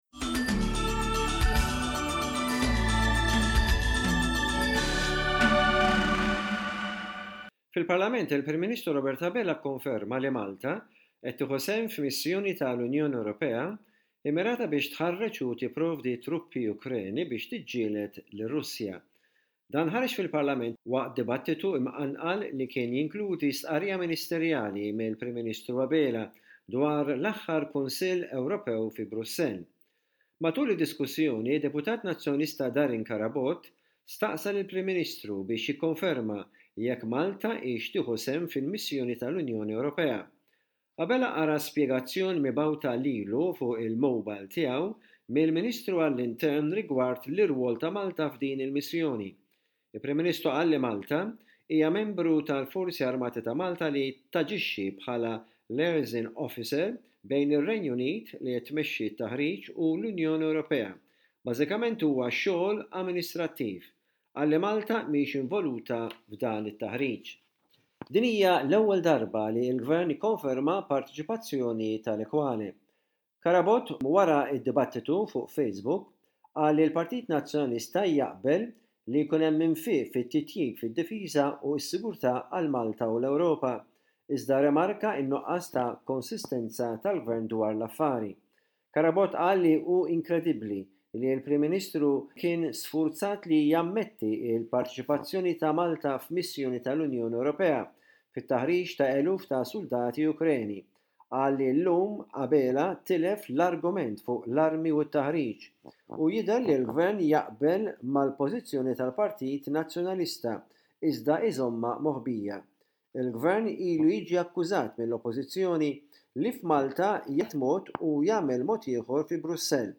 Aħbarijiet minn Malta: 29.03.25